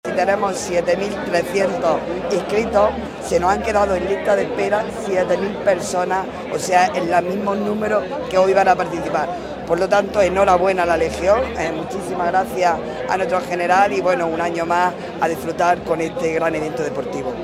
ARANZAZU-MARTIN-DELEGADA-GOBIERNO-JUNTA-SALIDA-DESERTICA.mp3